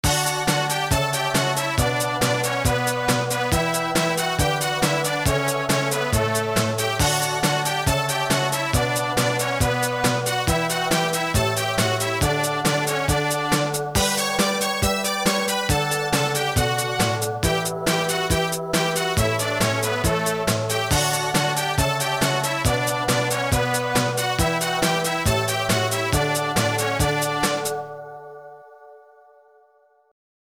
こちらは「冒険者が意気揚々と歩いていく曲」という発注で作っていただきました。
どれも３０秒程度の短い曲ですから、ループさせて使うと良いでしょう。